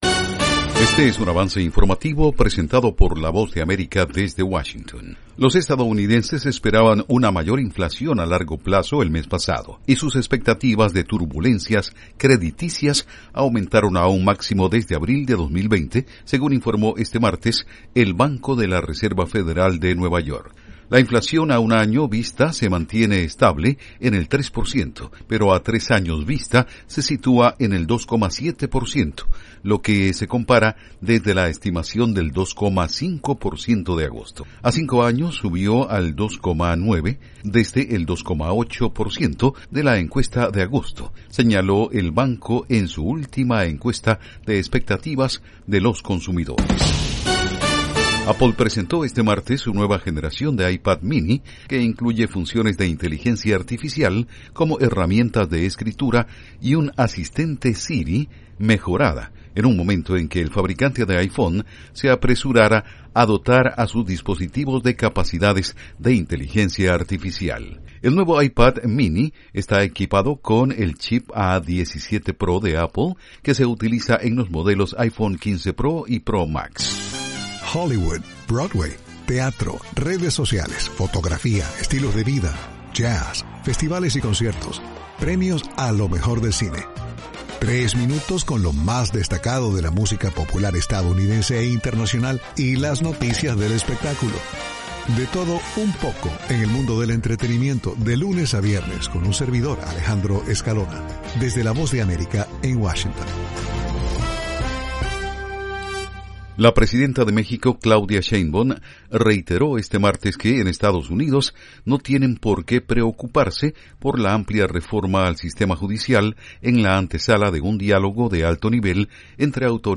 Avance Informativo 2:00 PM